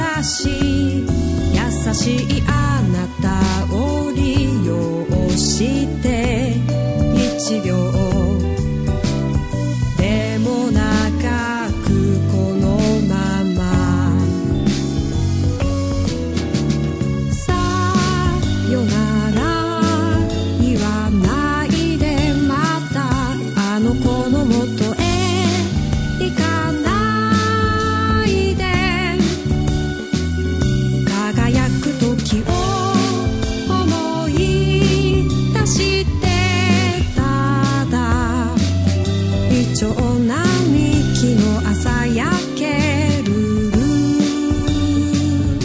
70年代ニューミュージックの再構築プロジェクト